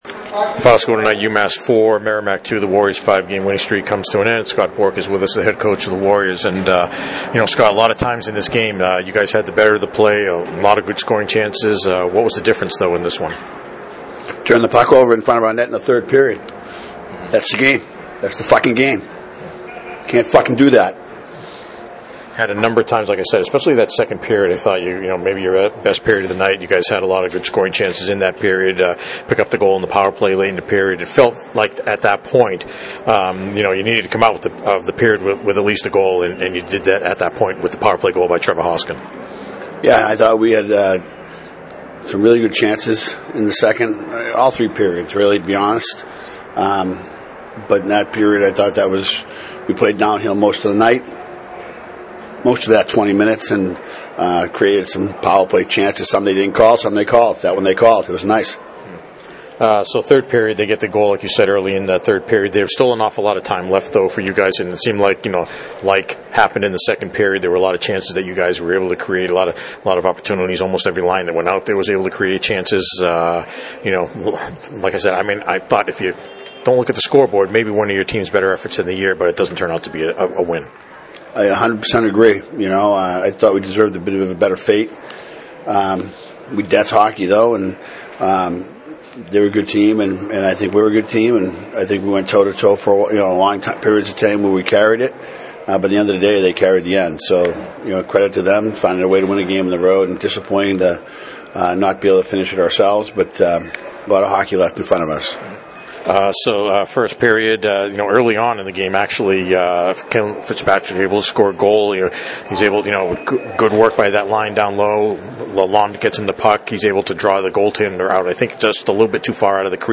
News, interviews and more about the Merrimack Warriors, an NCAA Division I hockey program that competes in the Hockey East Association at Merrimack College in North Andover, Mass.